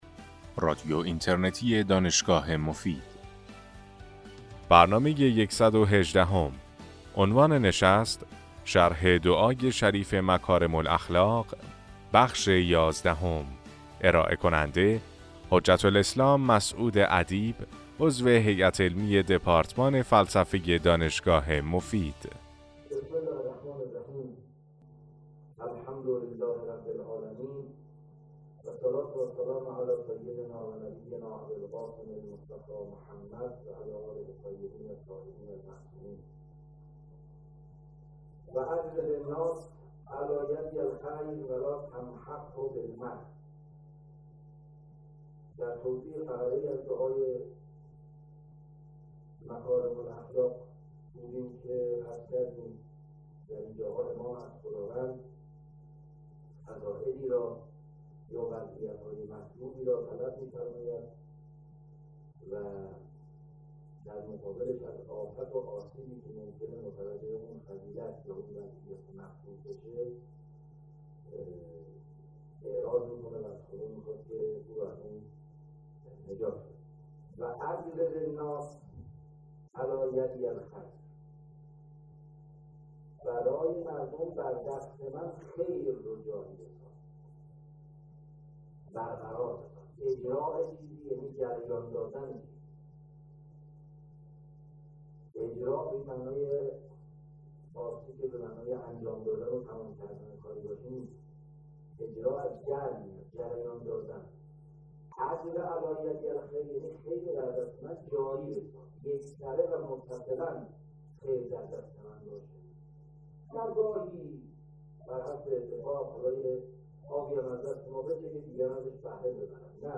سلسله سخنرانی